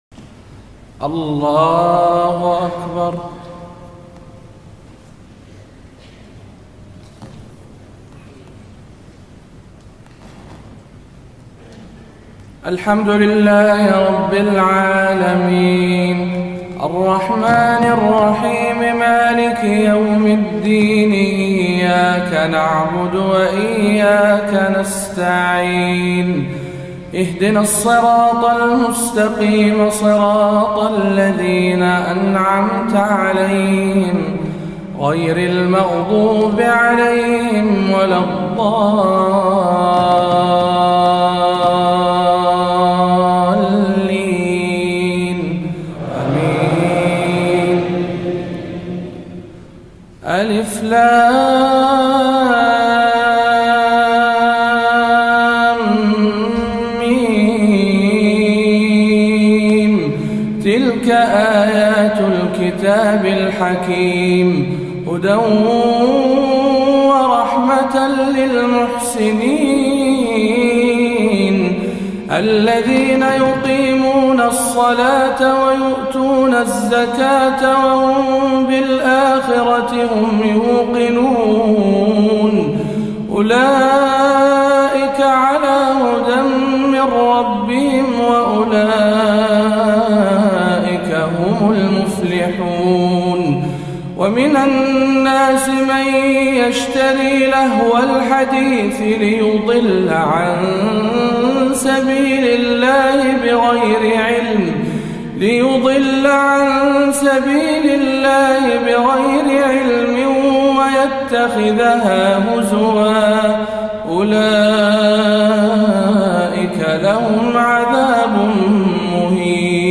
03. سورة لقمان بمسجد ضاحية جابر العلي بالكويت - رمضان 1437 هـ للقارئ